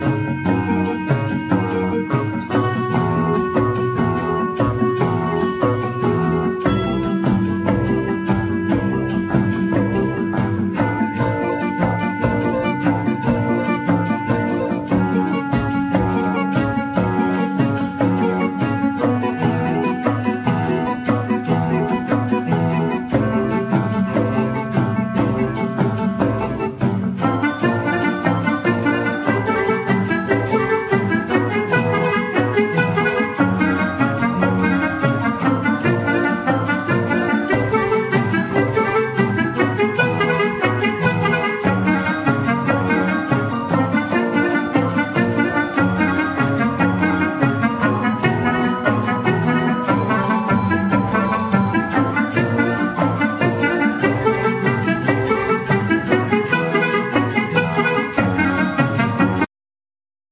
Clarinet
Keyboards,Short Waves
Cello
Percussions
Chapman Stick
Violin